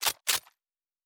Weapon 15 Reload 2.wav